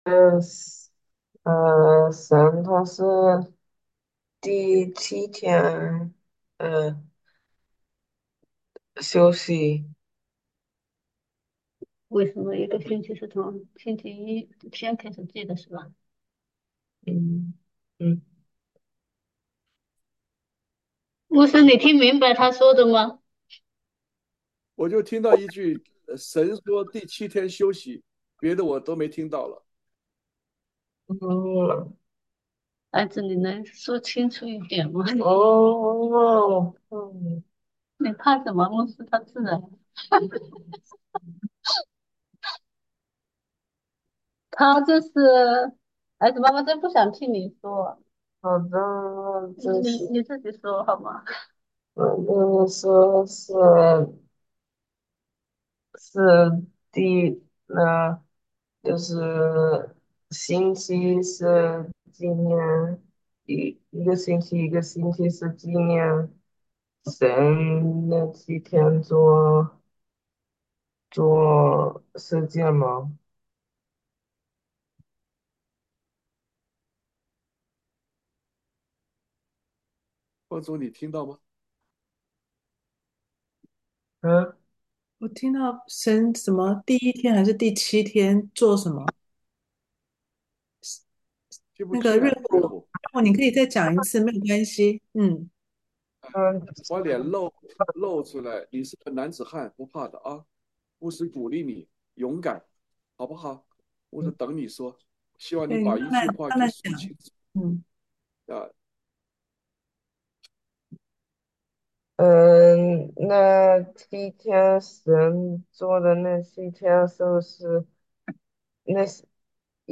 主日讲道 经文：使徒行傳Acts 21:15-25